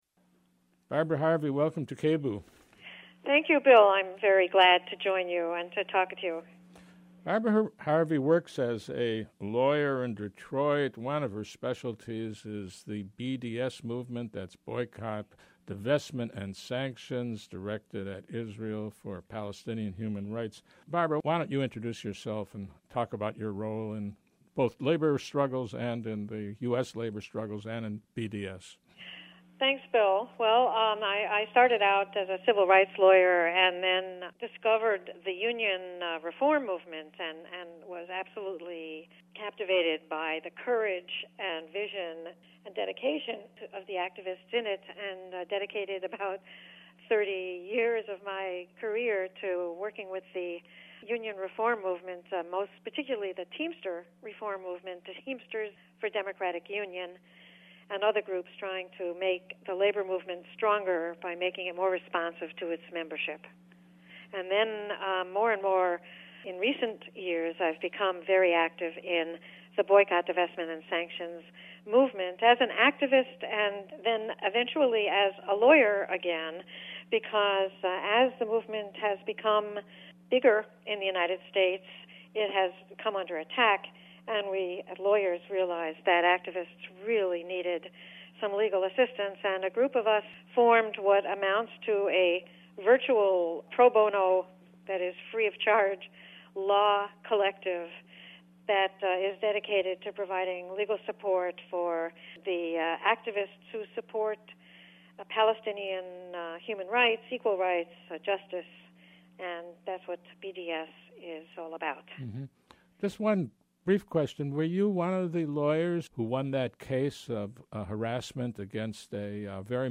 Public Affairs